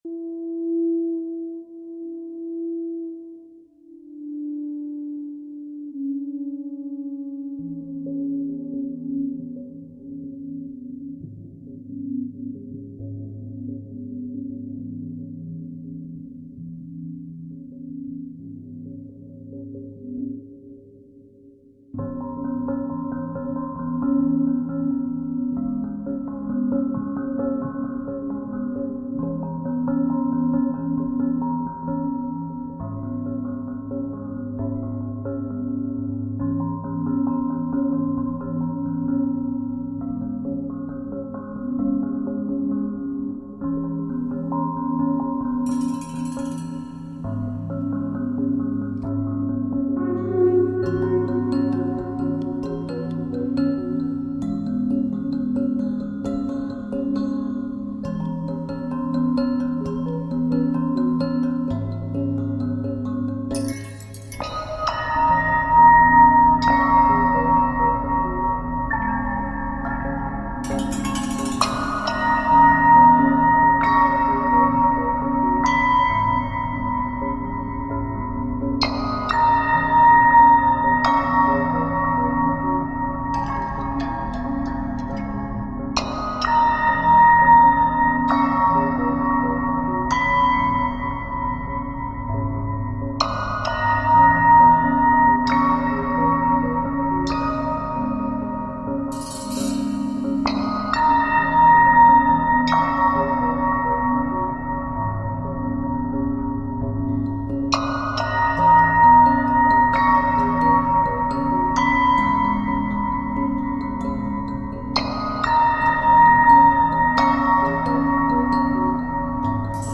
PAYSAGE MUSICAL
En utilisant seulement des objets trouvés transformés,